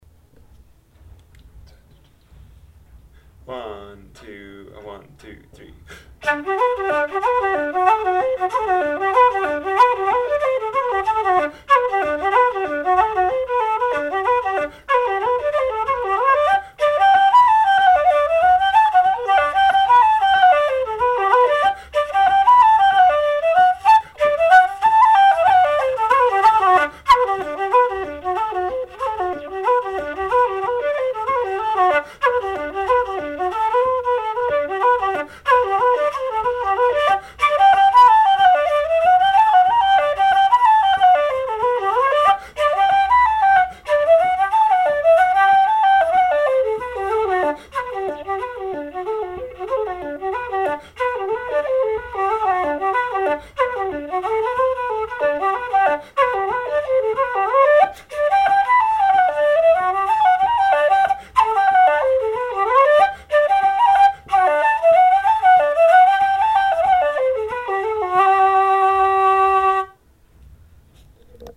REELS - Rolling in the Rye Grass & The Heathery Breeze
Heathery-Breeze-fast.mp3